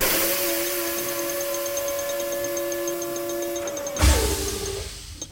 Garage.wav